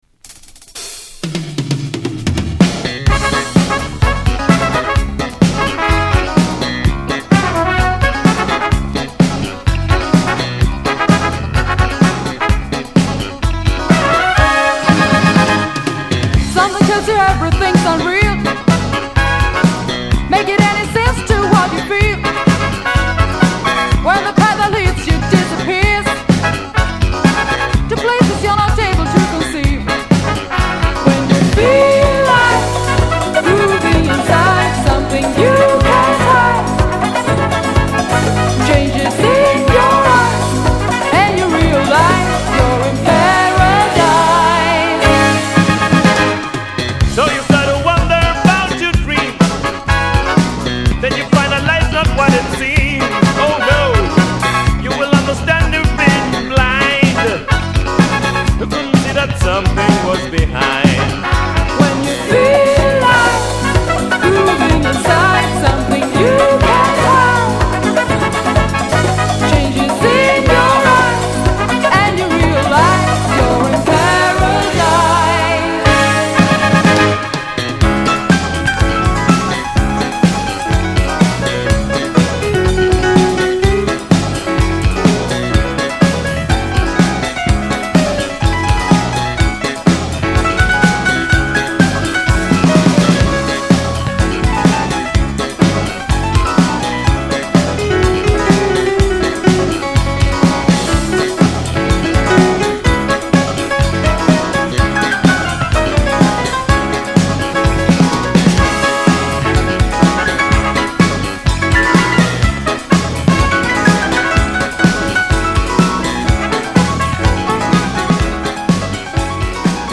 スウェーデンのピアノ奏者